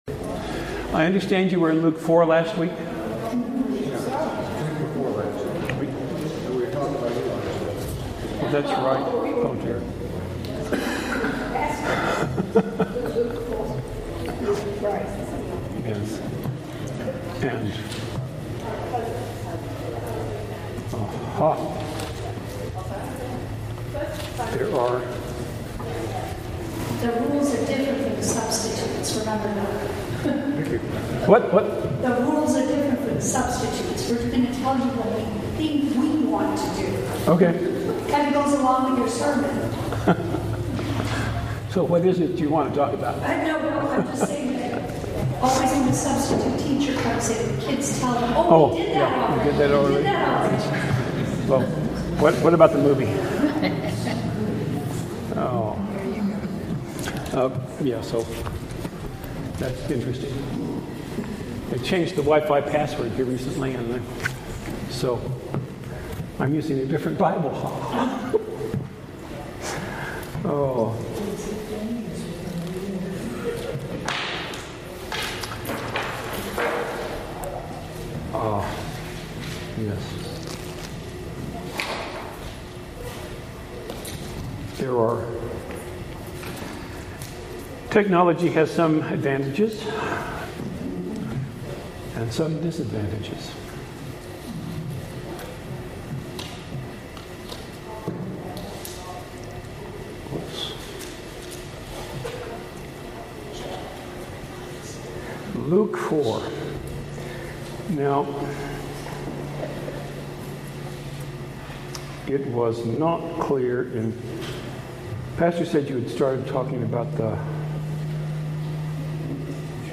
Passage: Luke 4:14-30 Service Type: Bible Study